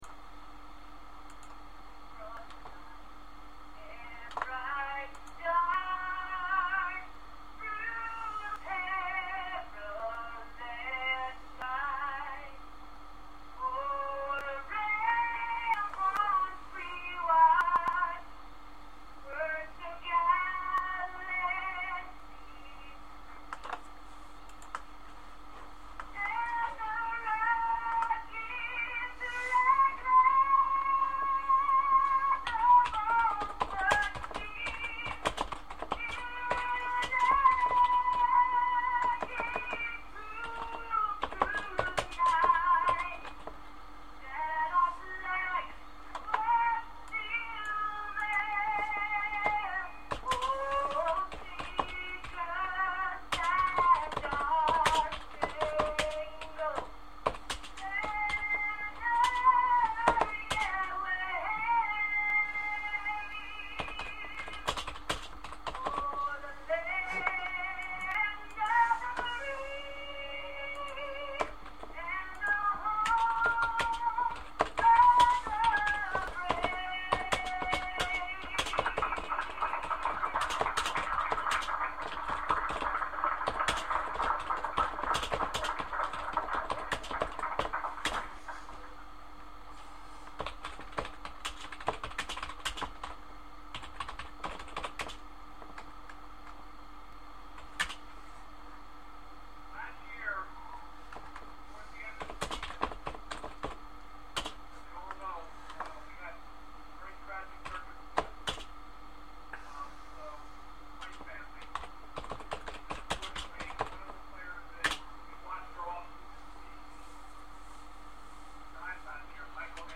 Catskill Little League opening report.